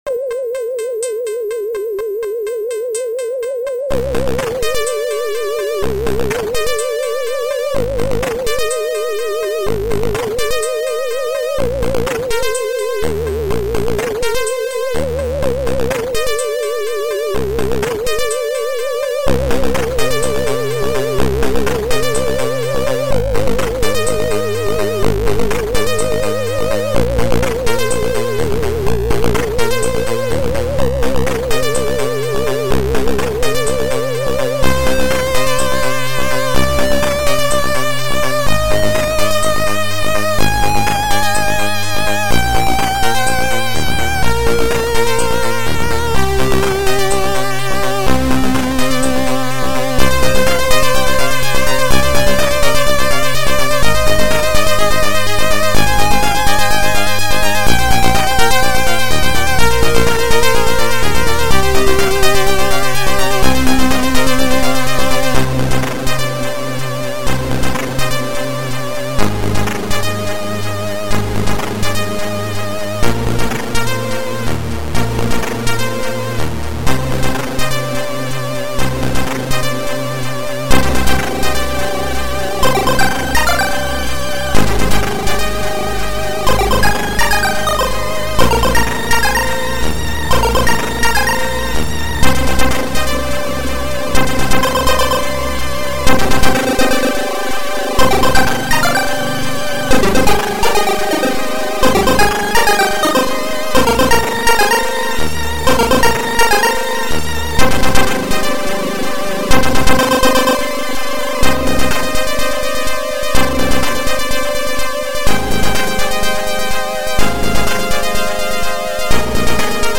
crack intro tune